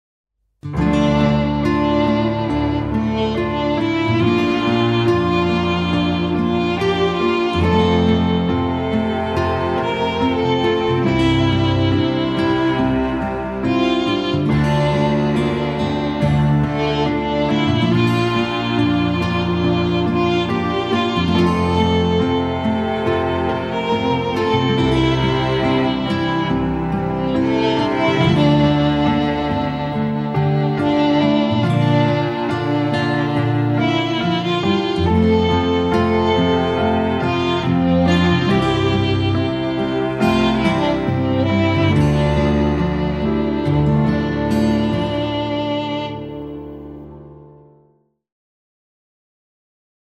intimiste - folk - melodieux - romantique - aerien